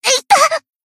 贡献 ） 分类:蔚蓝档案语音 协议:Copyright 您不可以覆盖此文件。
BA_V_Iori_Swimsuit_Battle_Damage_2.ogg